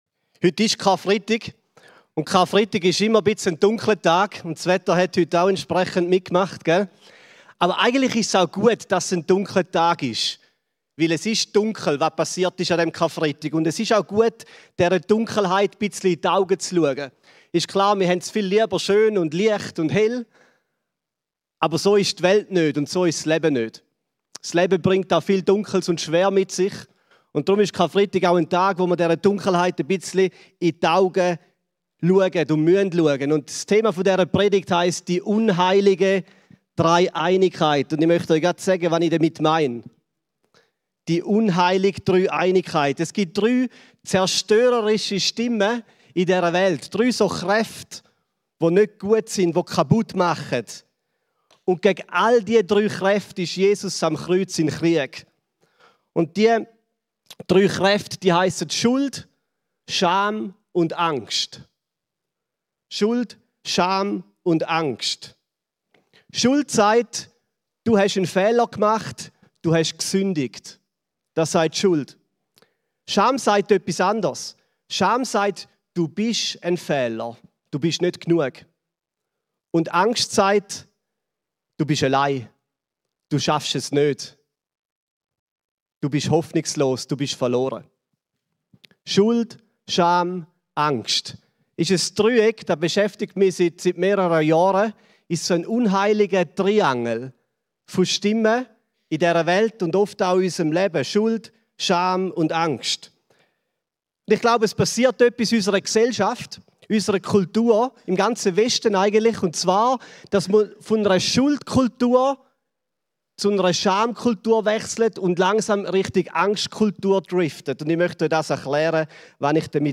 Weisst du was Jubel mit einem Schafbock zu tun hat? ...das erfährst du in dieser Predigt.